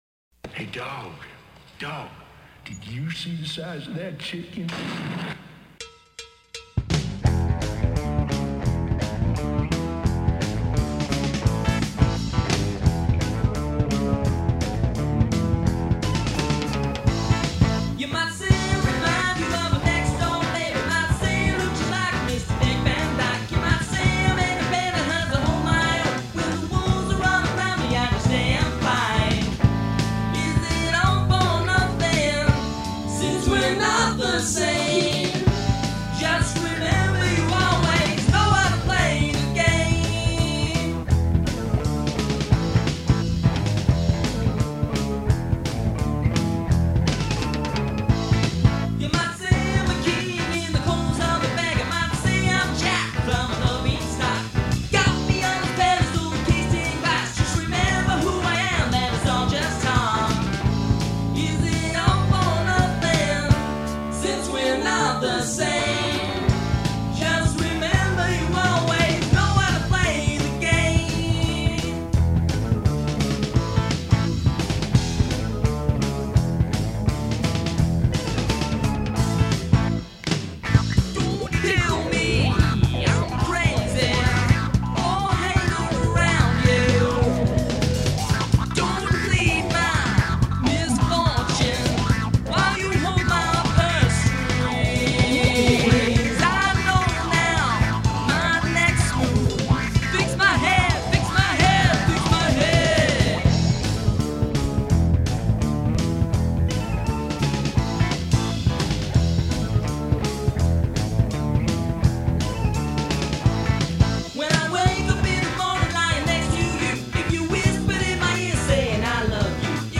…a jazz-fusion odyssey.